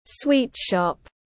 Sweet shop /ˈswiːt ˌʃɒp/